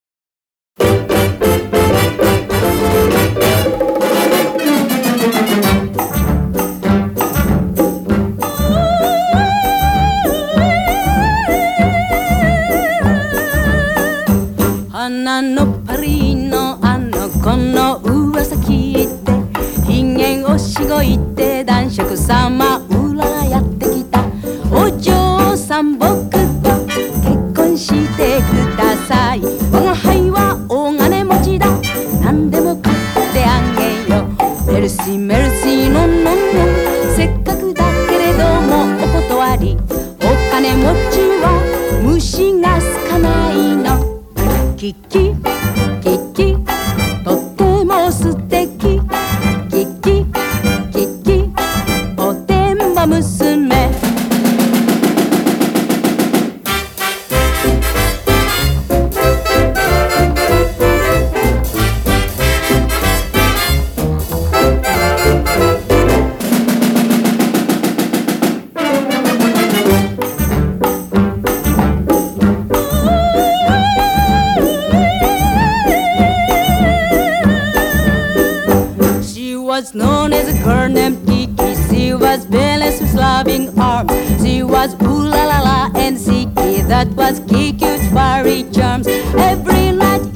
演奏もラテンジャズに近い小洒落たアレンジがナイス！